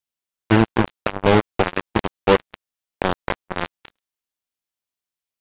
example-full-degrad.wav